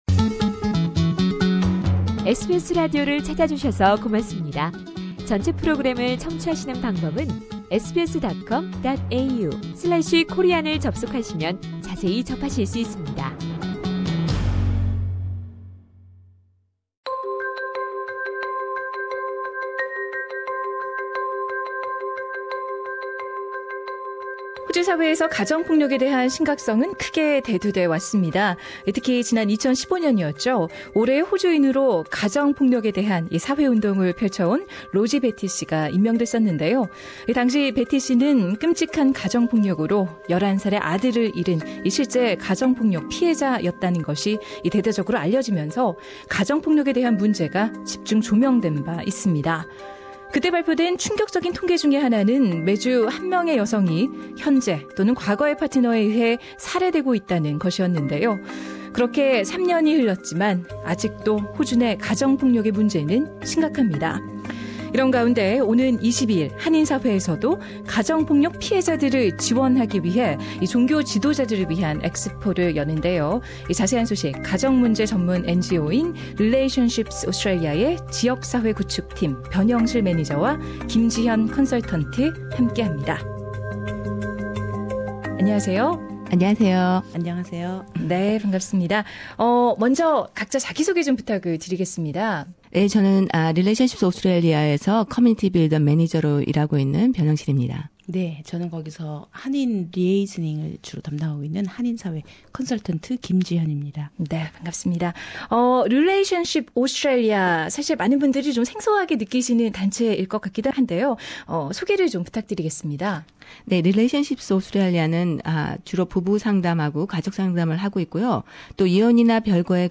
A full interview is available on podcast above.